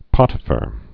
(pŏtə-fər)